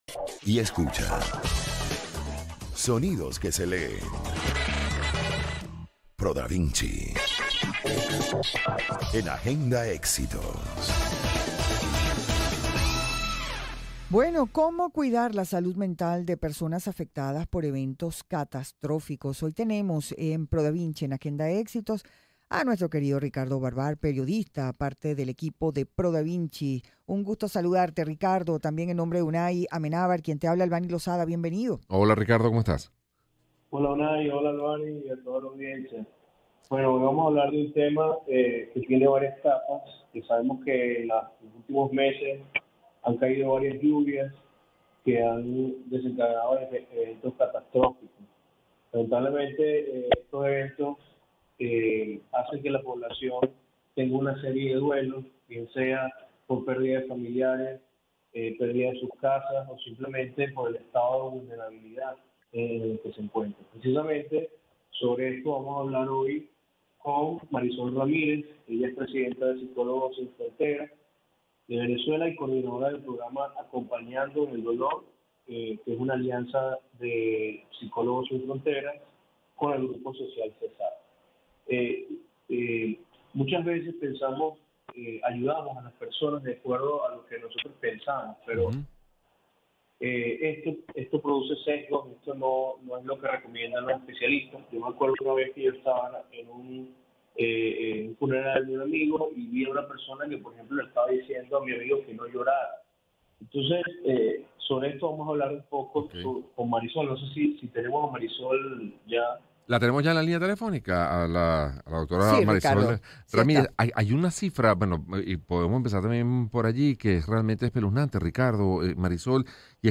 El programa fue transmitido a través de las emisoras del Circuito Éxitos de Unión Radio. Una entrevista